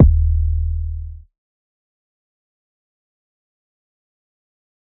MB 808 (28).wav